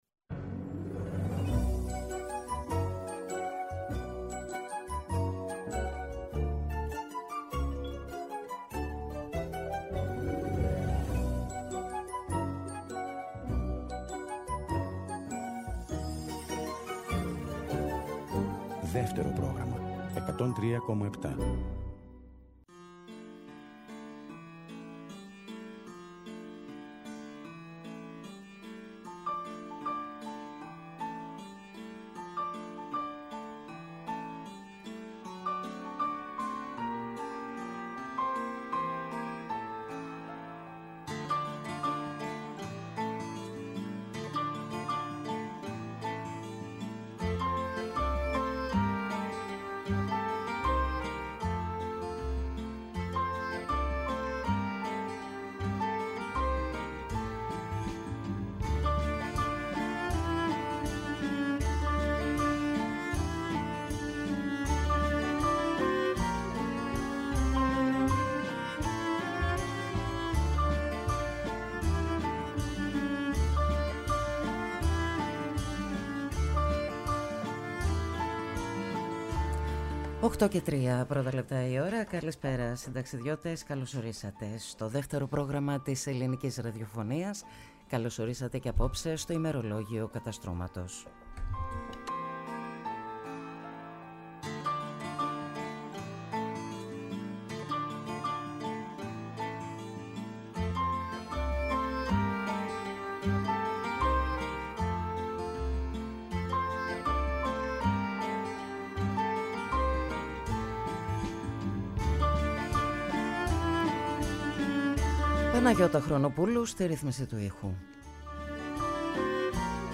Στο στούντιο του Δευτέρου Προγράμματος
Συνεντεύξεις